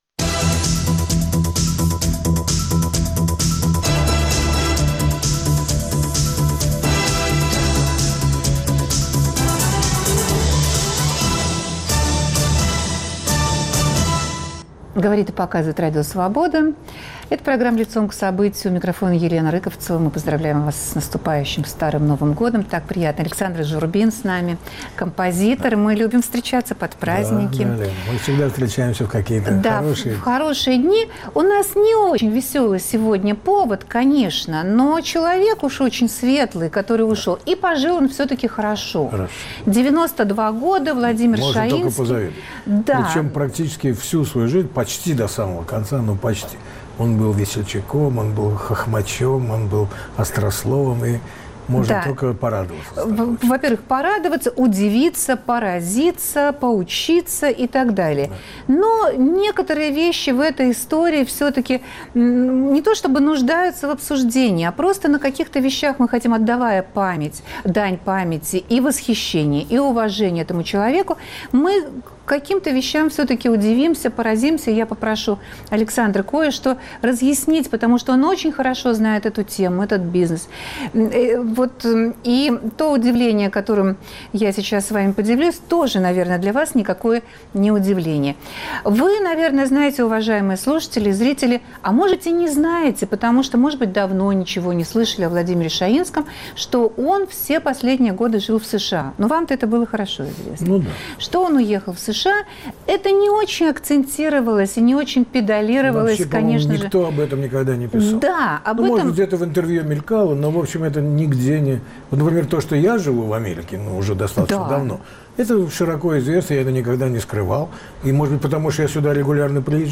Как случилось, что по прошествии уже более двух недель с момента смерти композитора он до сих пор не похоронен? Почему Россия не ценит свои духоподъемные музыкальные "символы"? Гости студии